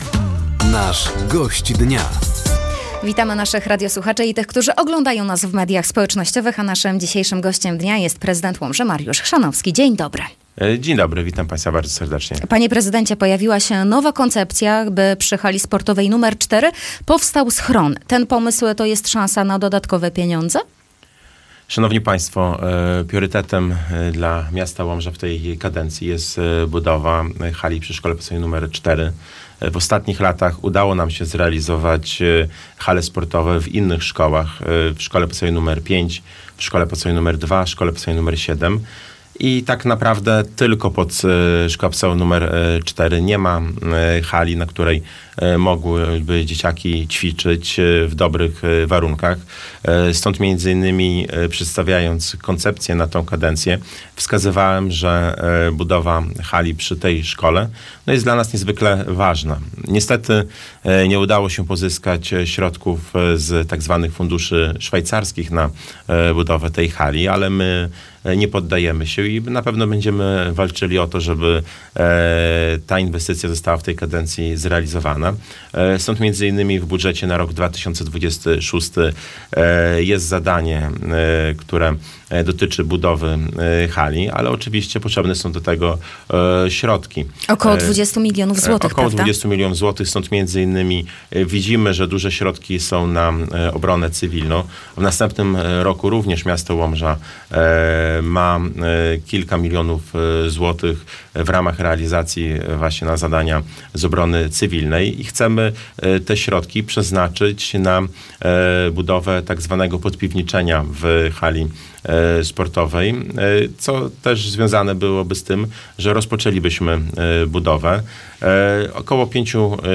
Zapraszamy do wysłuchania rozmowy z prezydentem Łomży, Mariuszem Chrzanowskim.